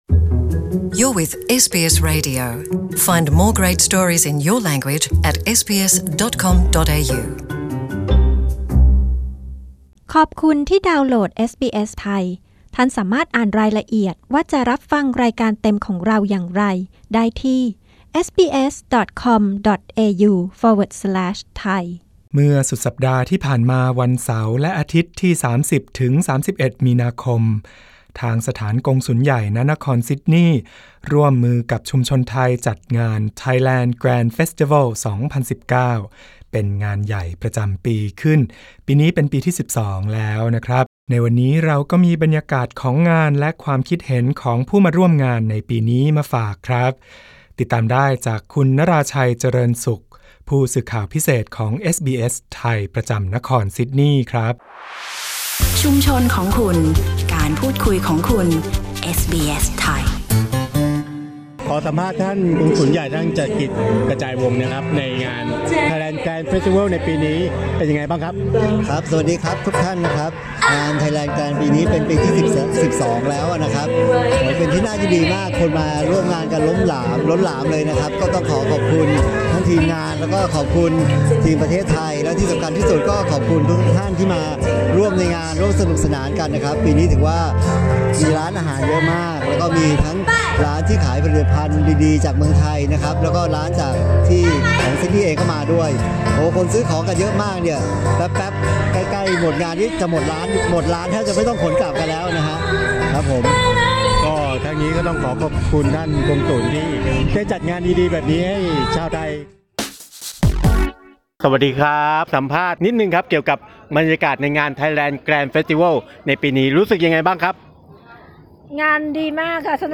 ฟังบรรยากาศงานไทยแลนด์ แกรนด์ เฟสติวัล 2019 ที่ทัมบาลอง พาร์ค ในซิดนีย์ เมื่อเสาร์อาทิตย์ที่ผ่านมา งานนี้ประสบความสำเร็จมากน้อยแค่ไหนปีนี้ ทั้งผู้มาออกร้าน และผู้มาร่วมงาน รู้สึกประทับใจอย่างไร ติดตามได้ จากรายงานพิเศษโดยผู้สื่อข่าวของเอสบีเอส ไทย ประจำซิดนีย์ ที่ไปสัมภาษณ์ผู้คนหลากหลายในงานมาให้ฟังกัน